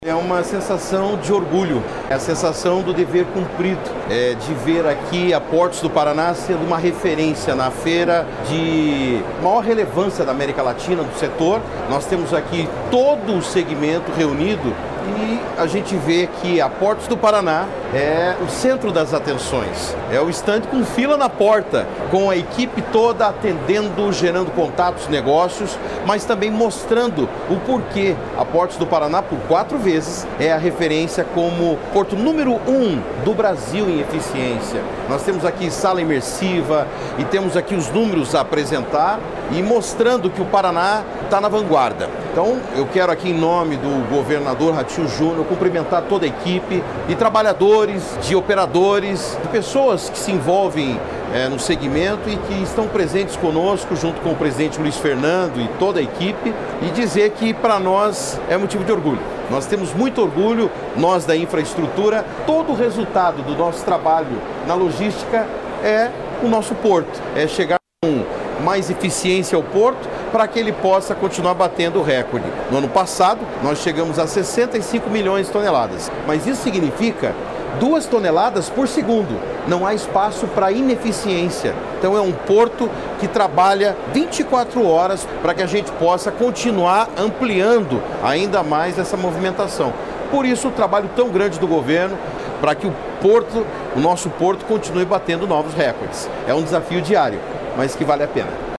Sonora do secretário de Infraestrutura e Logística, Sandro Alex, sobre a Portos do Paraná ser referência para o setor no Brasil